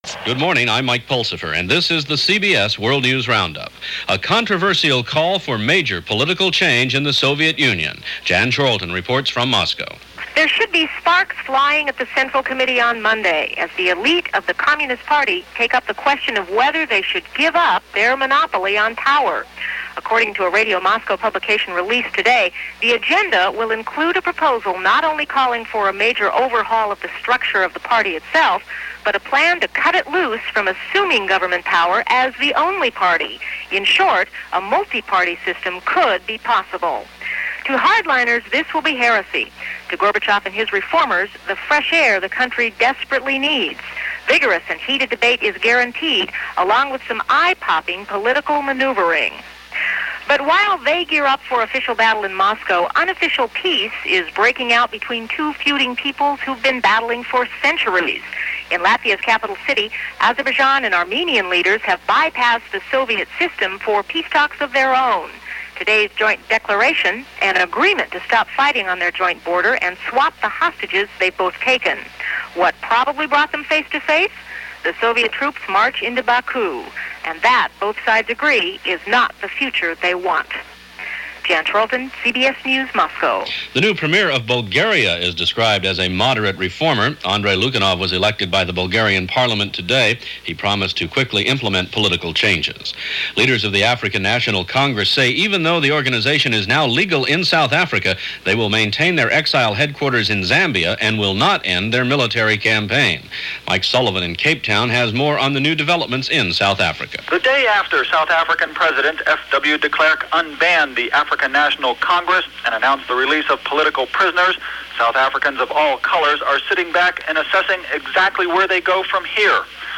[laterpay_premium_download target_post_id=”49016″ heading_text=”Download For $1.99:” description_text=”February 3, 1990 – CBS World News Roundup – Gordon Skene Sound Collection” content_type=”link”]